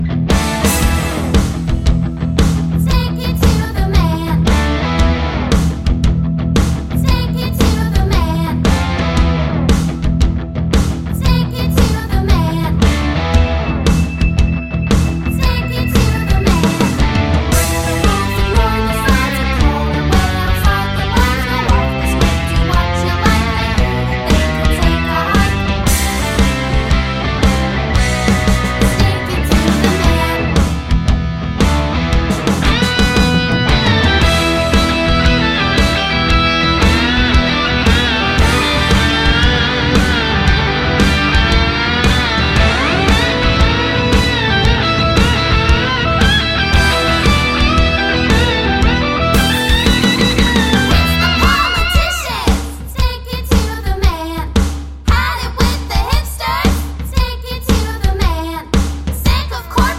no Backing Vocals Musicals 3:13 Buy £1.50